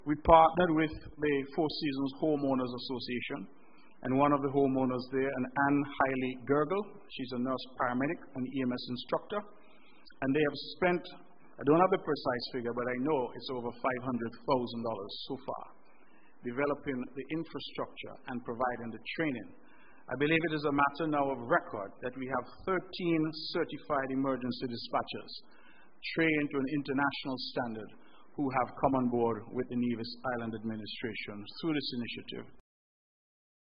Additionally, Premier Brantley disclosed that all the final testing for a state-of-the-art 911 emergency dispatch system has been concluded and that it would go live soon: